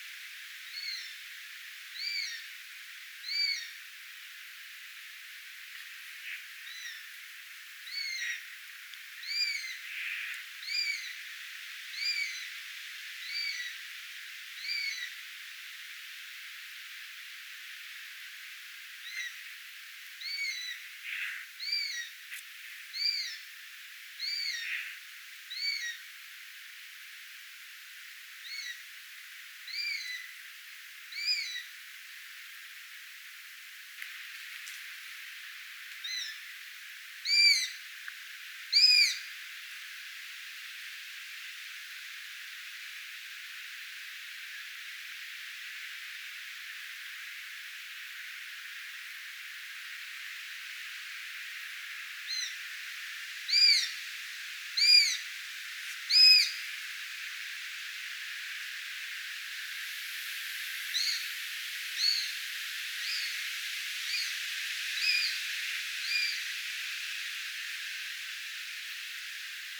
Pitkänrannan lintutornin virellä metsikössä
varpuspöllön iso poikanen kerjää??
Arvaan, että äänitteellä kuuluva ääni
on isojen lentopoikasten ääntä.
ilmeisesti_varpuspollon_lentopoikasen_ison_kerjuuaanta.mp3